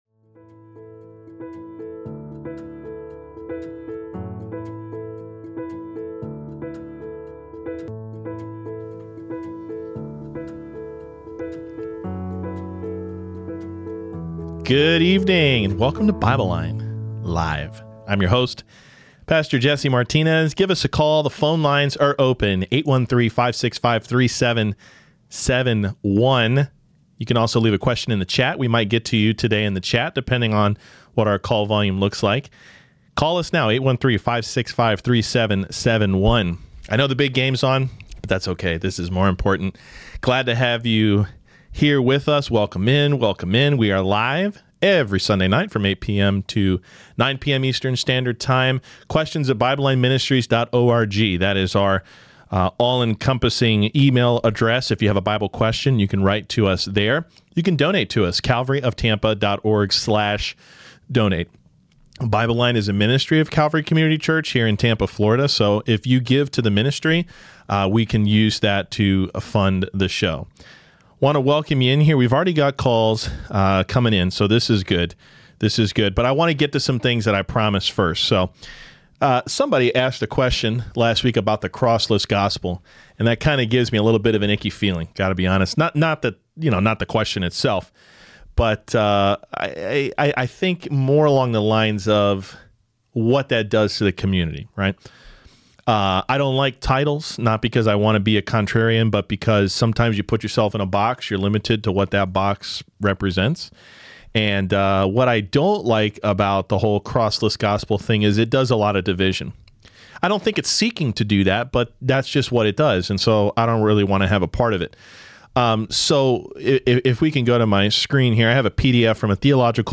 BibleLine LIVE QNA Replay | Rapture, Multiple Wills of God, Dispensationalism, Apparel, and more!